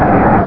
pokeemerald / sound / direct_sound_samples / cries / slaking.aif